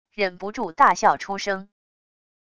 忍不住大笑出声wav音频